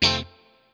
CHORD 7   AA.wav